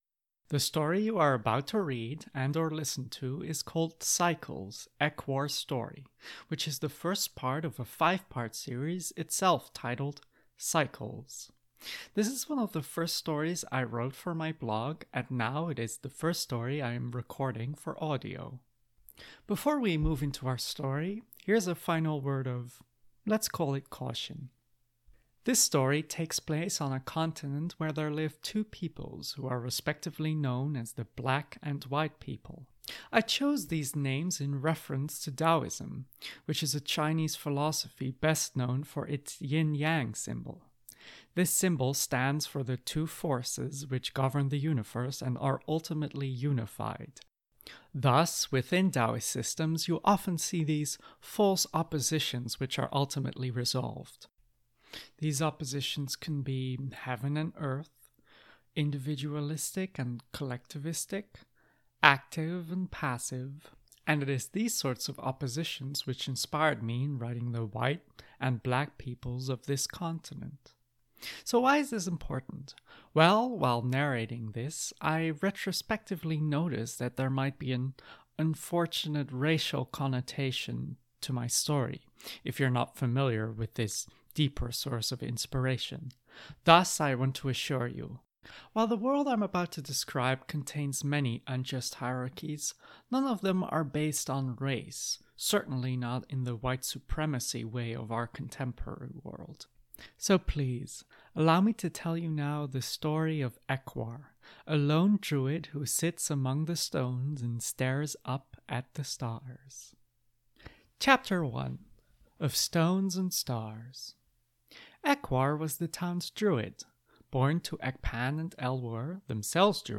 This is one of the first stories I wrote for my blog, and now it is the first story I am recording for audio.